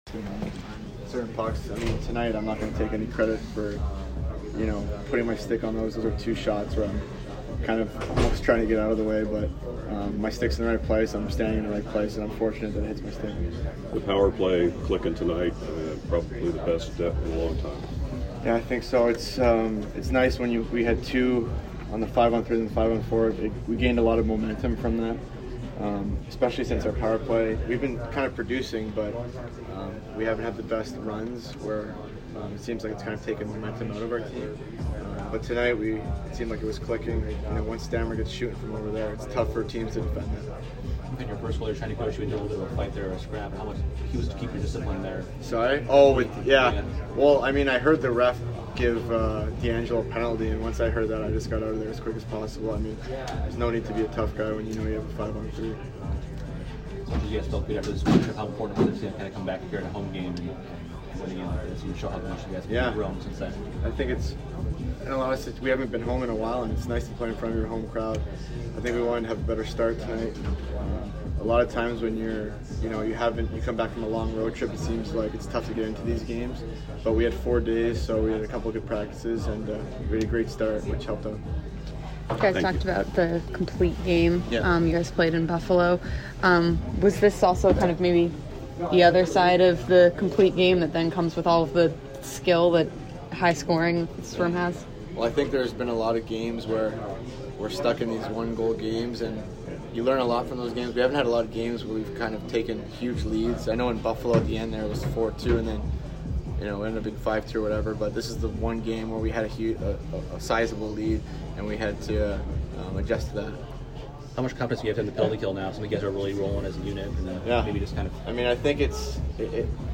Alex Killorn Post-game 11/14